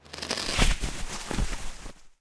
WAV · 96 KB · 單聲道 (1ch)